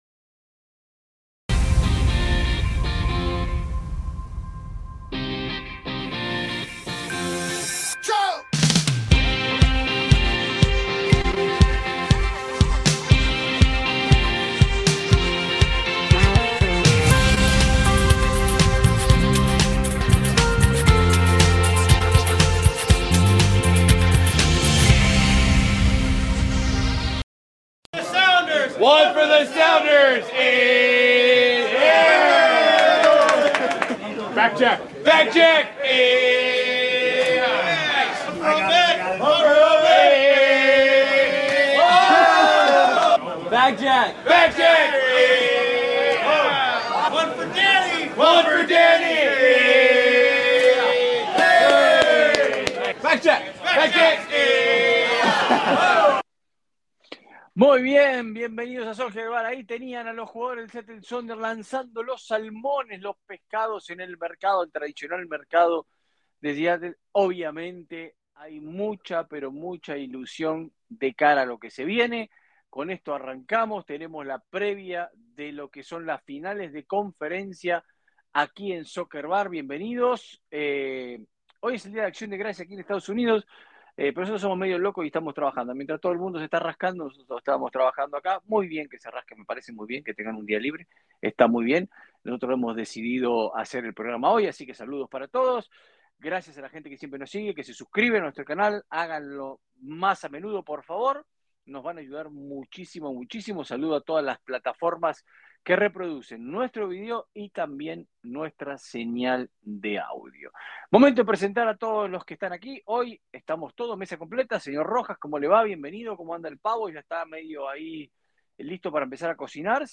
Ponemos la mesa de cara a las finales de Conferencia, escuchamos protagonistas y debatimos si esta 2024 es la Liga que solo LA Galaxy puede perder. Debate y más noticias de MLS.